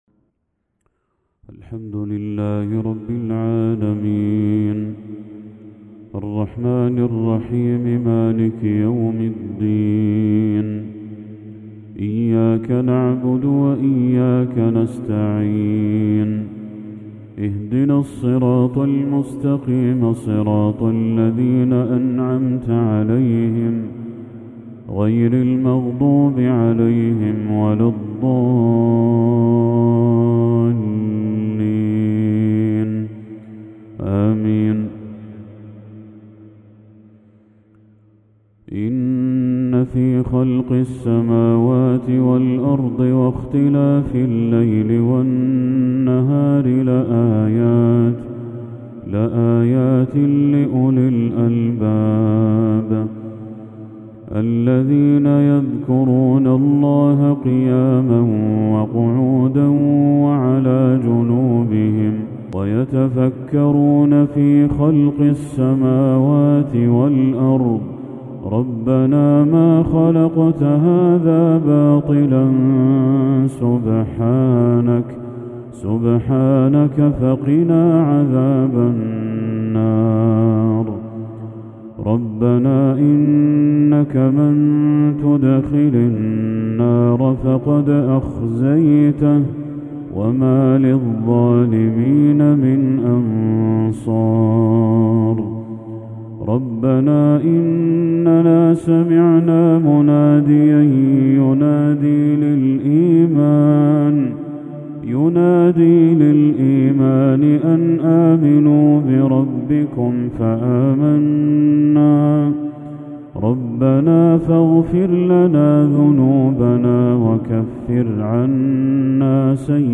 تلاوة جميلة للشيخ بدر التركي خواتيم سورة آل عمران | عشاء 24 ذو الحجة 1445هـ > 1445هـ > تلاوات الشيخ بدر التركي > المزيد - تلاوات الحرمين